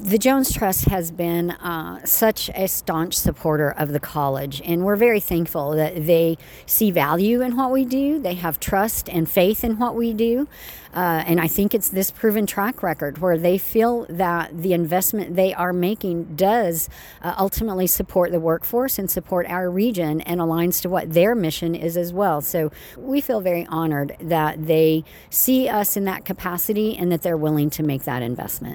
During the kickoff event for the campaign on the FHTC campus Thursday morning, FHTC leadership unveiled a major lead gift from the Jones Trust of $2 million.